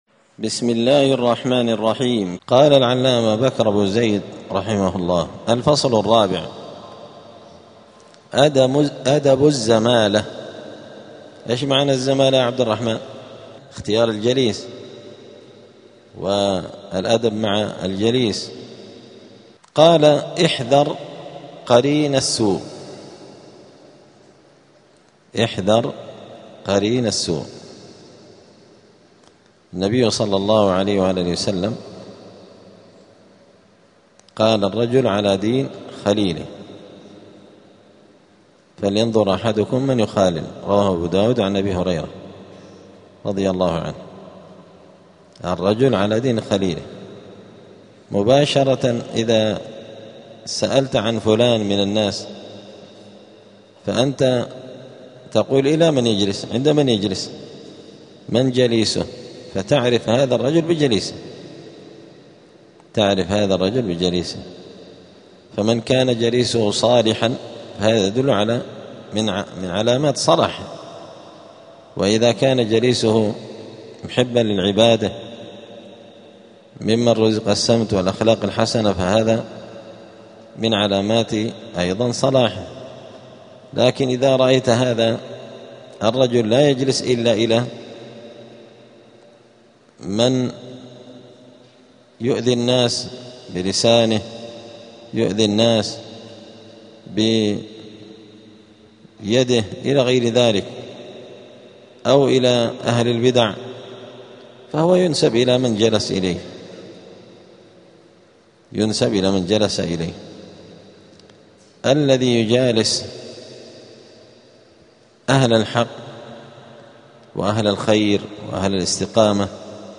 *الدرس الثامن والأربعون (48) {أدب الزمالة}.*